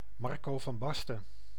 Marcel "Marco" Van Basten[2] (Dutch pronunciation: [ˈmɑrkoː vɑm ˈbɑstə(n)]
Nl-Marco_van_Basten.ogg.mp3